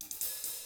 129BOSSAI1-L.wav